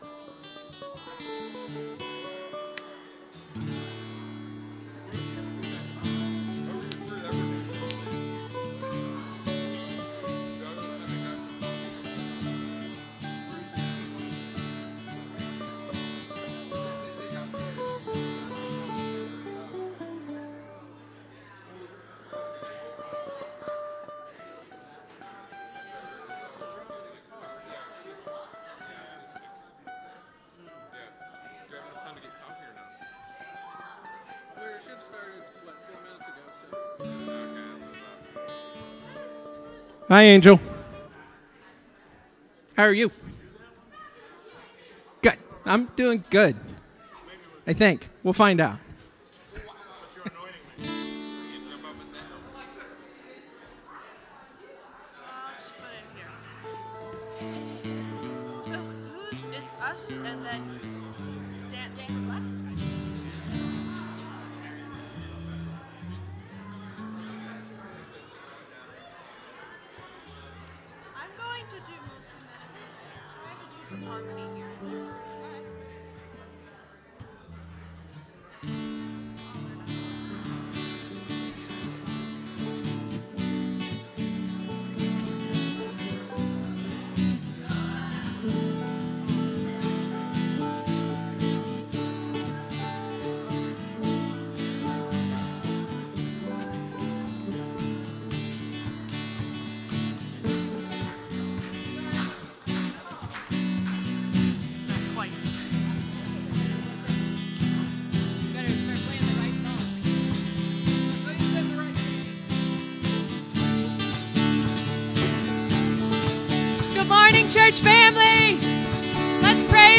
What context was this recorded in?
This week is Celebration Sunday! Also known as All Worship Sunday, it is a time to sing and dance a little more, pray, and tell others what God is doing in your life.